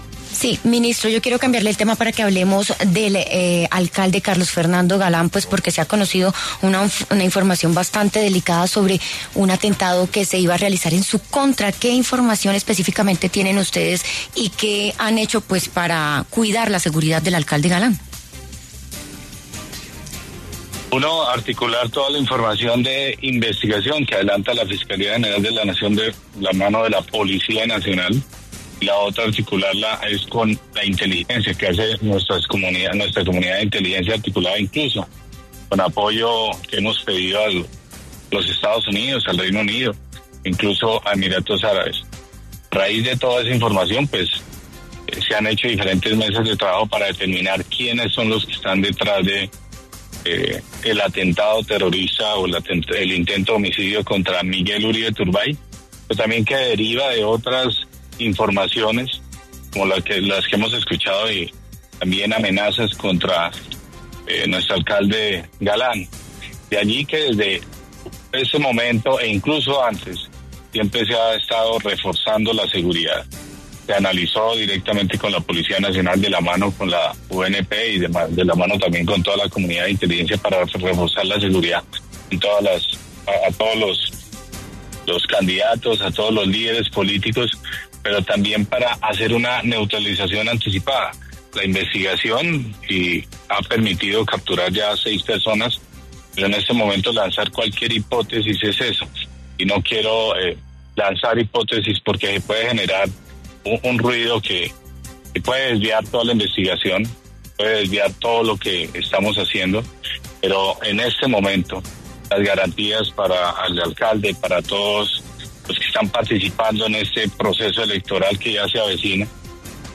Al respecto, en los micrófonos de La W, con Julio Sánchez Cristo, habló el ministro de Defensa, Pedro Sánchez, quien se refirió al tema.